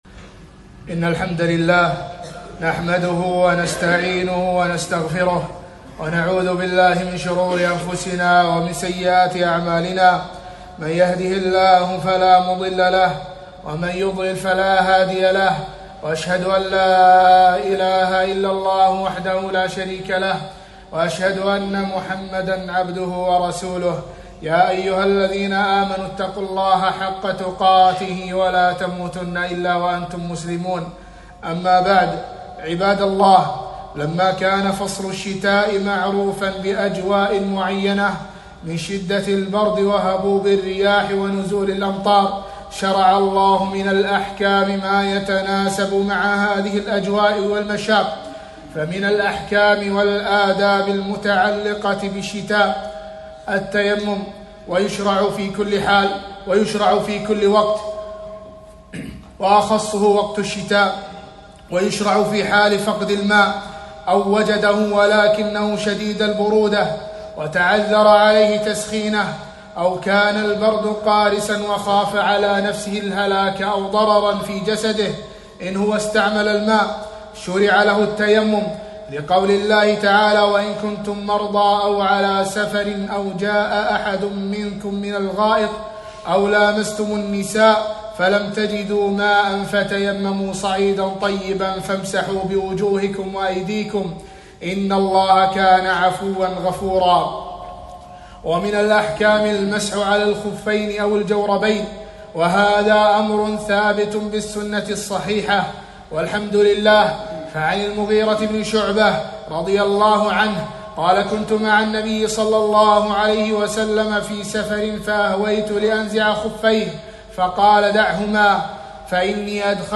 خطبة - أحكام الشتاء